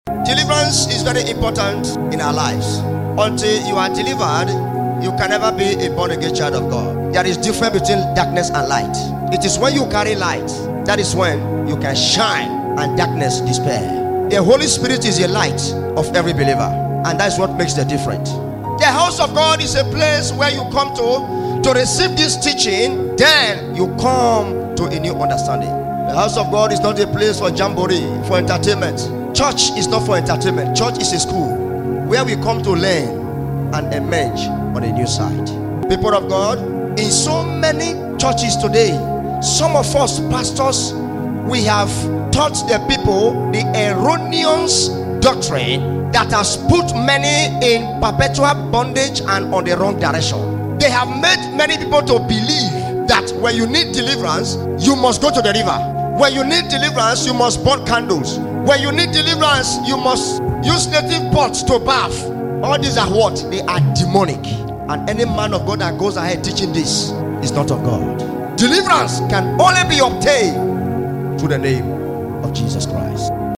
Live At More Grace Prayer Sound Effects Free Download